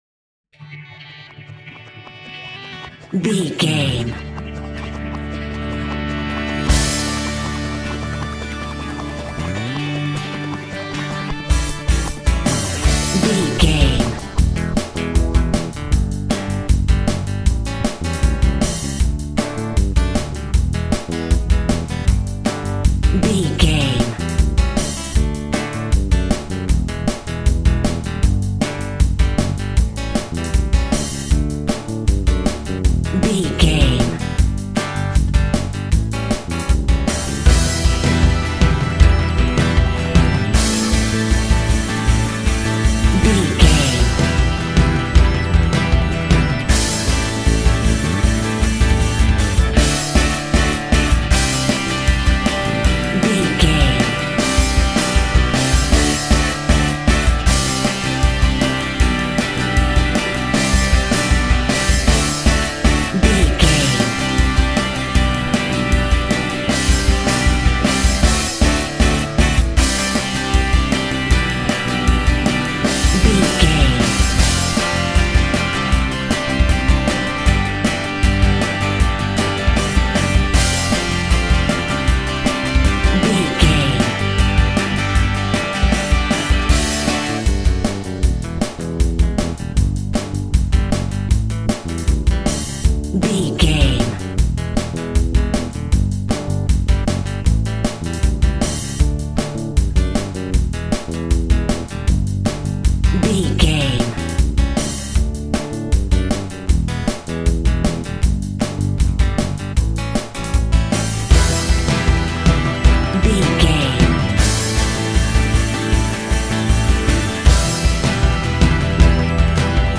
Uptempo Pop Rock Music.
Ionian/Major
D
energetic
uplifting
electric guitar
bass guitar
drums